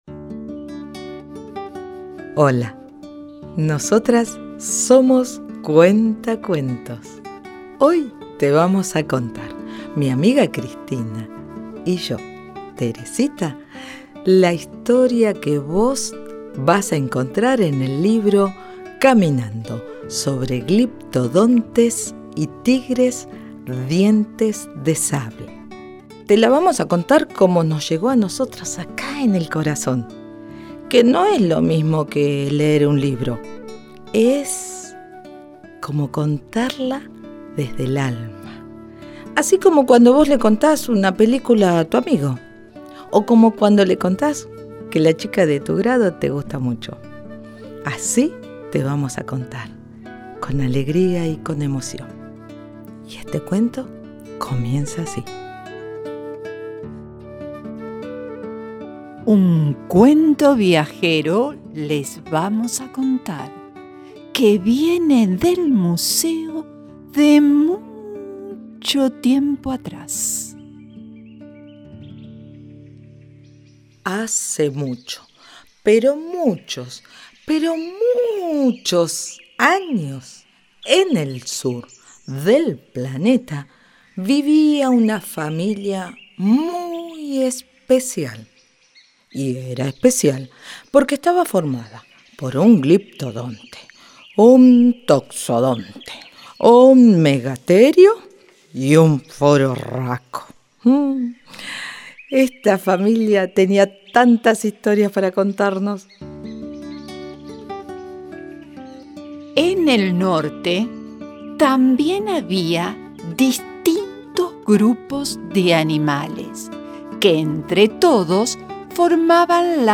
Audiocuento_-_Caminando_hacia_tierras_nuevas.mp3